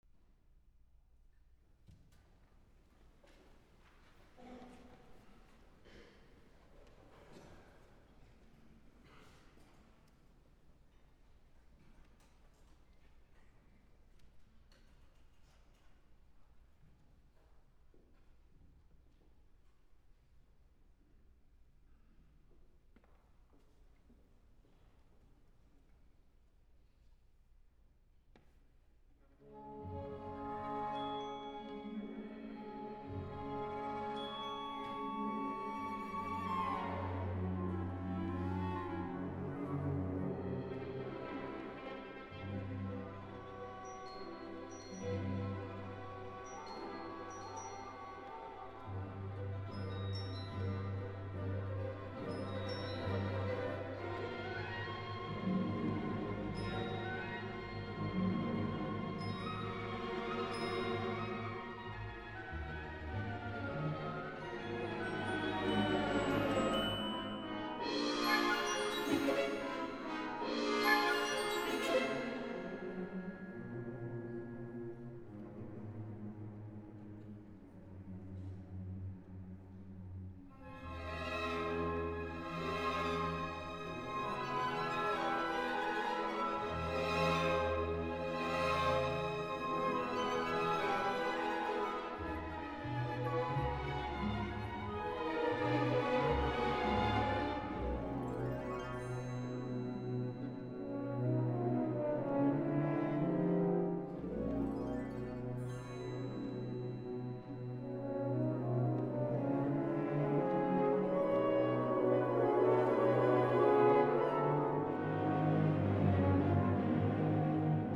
CDs contain a total of 15 classical music tracks.